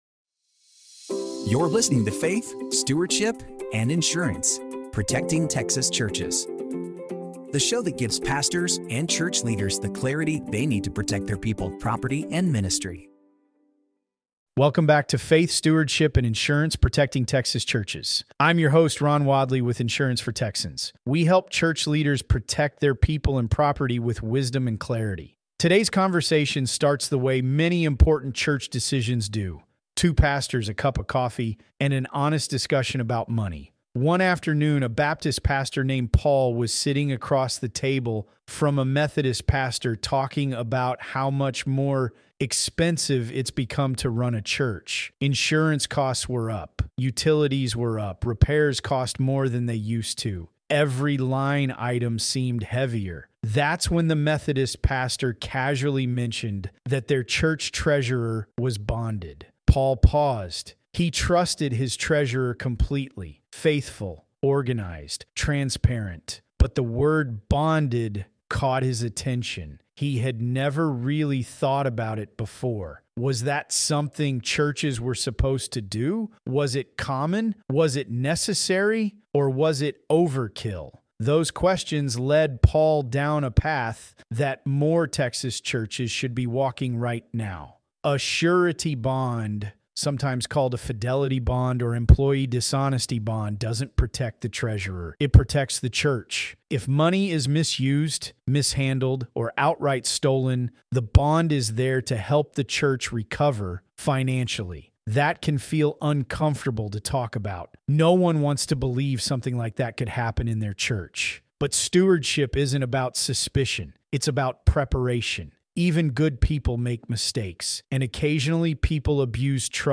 Through a conversation between two pastors about rising church costs, the topic of surety bonds is discussed. These bonds protect churches from financial mismanagement or theft, reinforcing trust and transparency in church financial operations. The episode highlights the affordable nature of surety bonds and the necessity for churches to review their insurance policies to cover potential gaps.
00:25 A Conversation Between Pastors